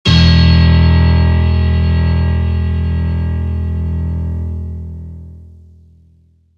HardAndToughPiano